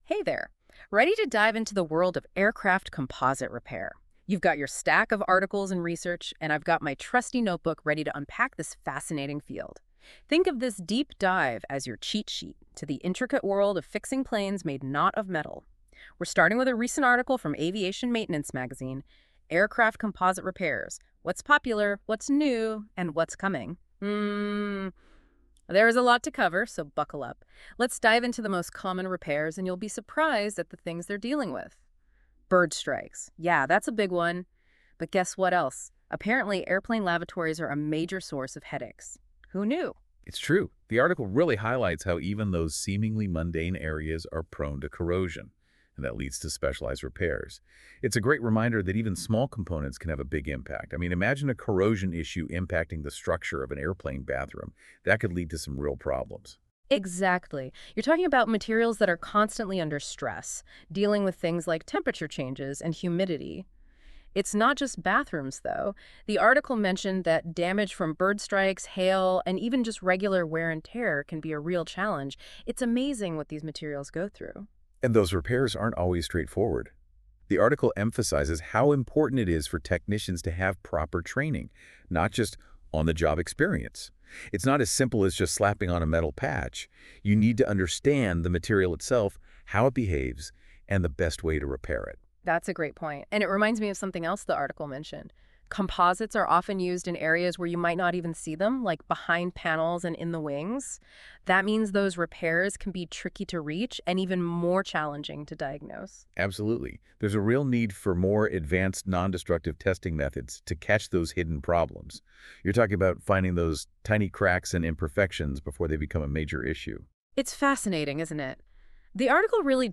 This podcast from Aviation Maintenance Magazine focuses on the growing use of composite materials in aircraft construction and the challenges and advancements in repairing these materials. The podcast features interviews with experts from IAI Bedek Aviation Group, M1 Composites Technology, and Abaris Training Resources, who discuss common composite repairs, recent advances in repair techniques, ongoing challenges, and future trends in the field. The podcast highlights the importance of specialized training for mechanics to ensure the airworthiness of composite repairs and proposes solutions to address the complexities of repairing these increasingly prevalent materials.